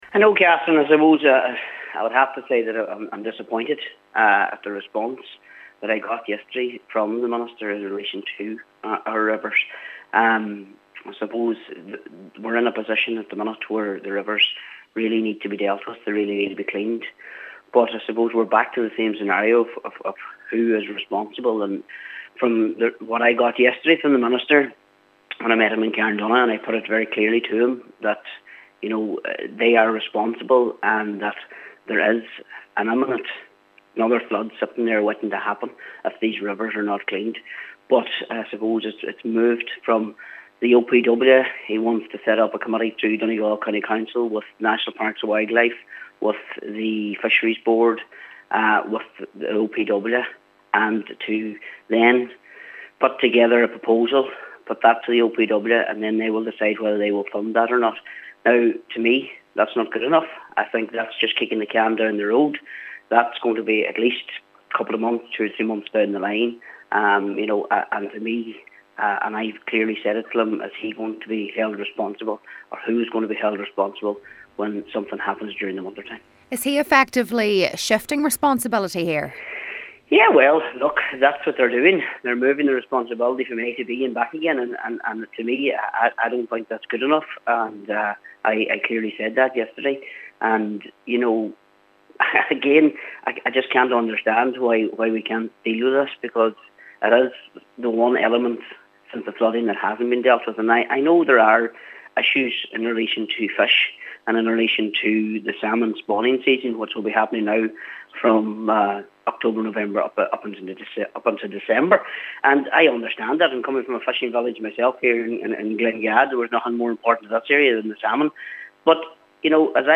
While acknowledging that there are other elements to contend with, Cllr. McDermott says the response is simply not good enough: